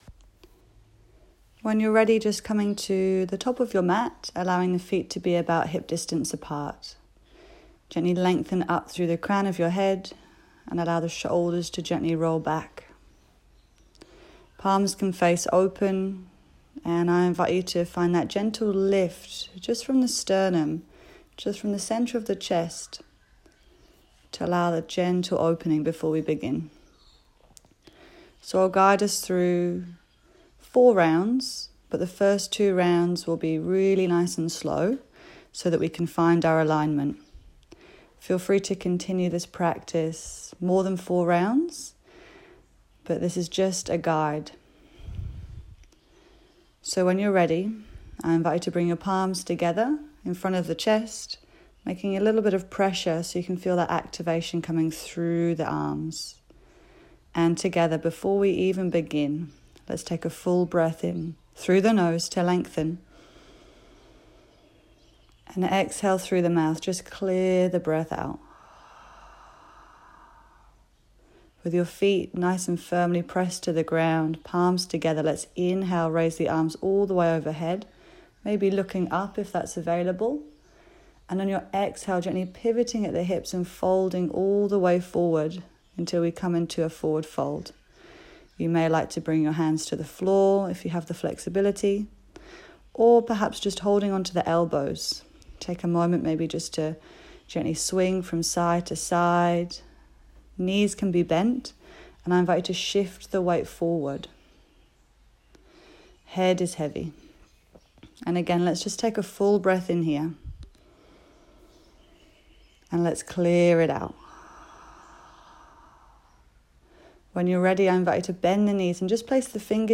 Sun Salutations - Guided Yoga Practice